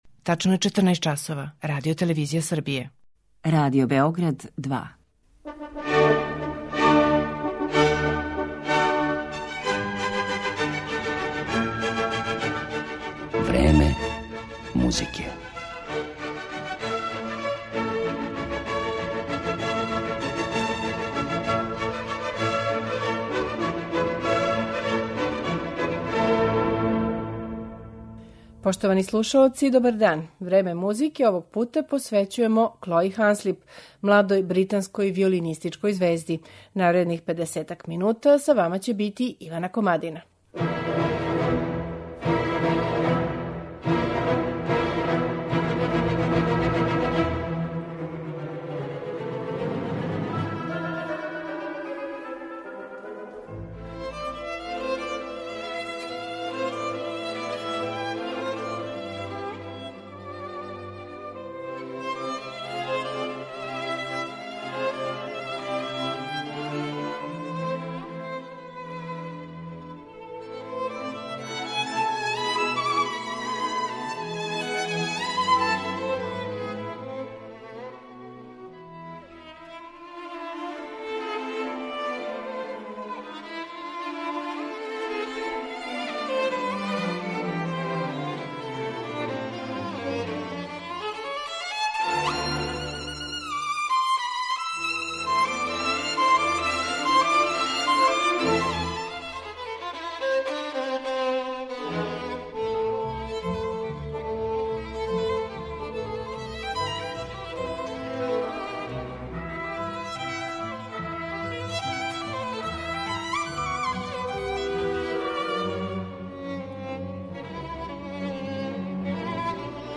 КЛОИ ХАНСЛИП, виолина